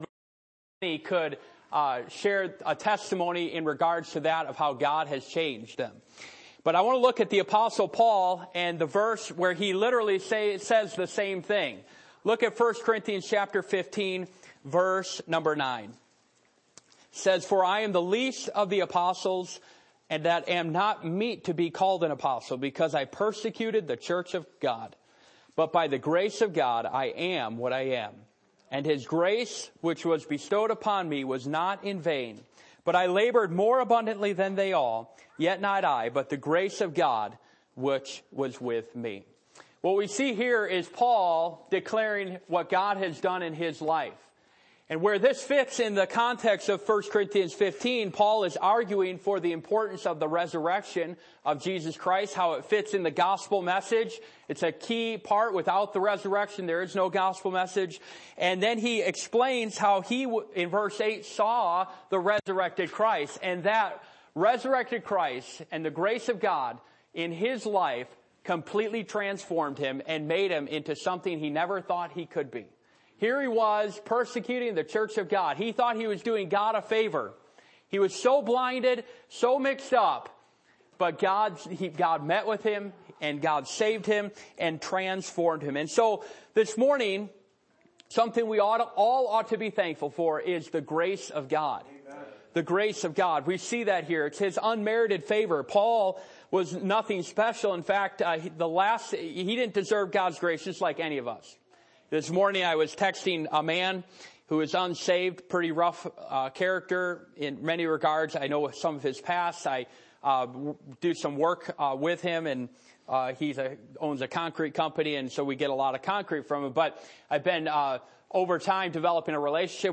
1 Corinthians 15:10 Service Type: Thanksgiving %todo_render% « Fearless in the Fight How Has God’s Love Affected Your Life?